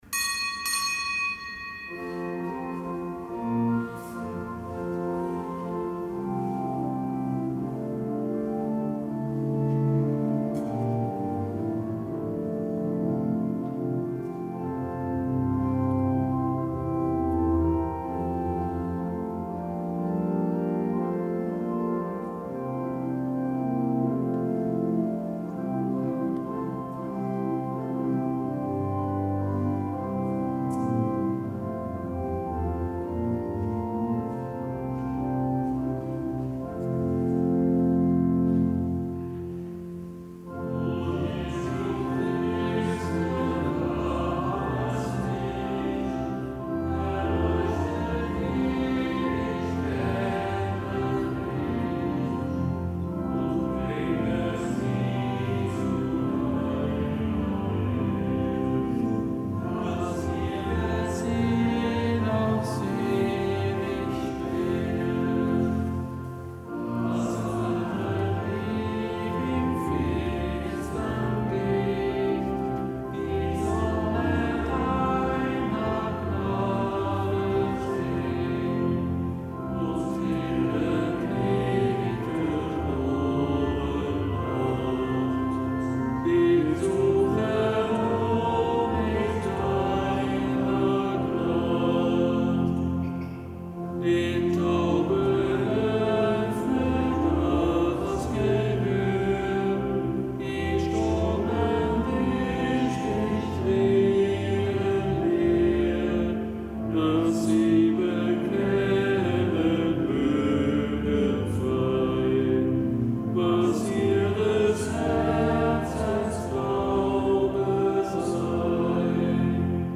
Kapitelsmesse aus dem Kölner Dom am Freitag der fünften Woche im Jahreskreis.